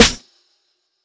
Snares
FGS_SNR.wav